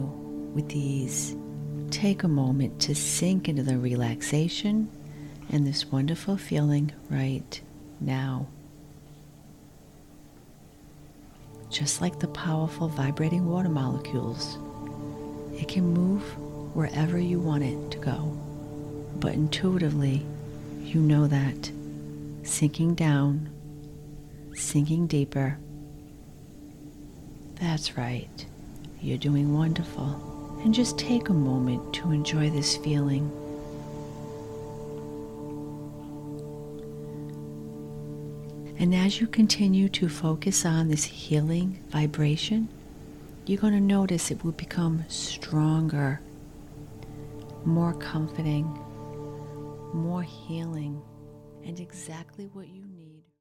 Here are a few clips…some have music in the background, some do not.
Energetic Healing Clip
Energetic-Healing-clip.mp3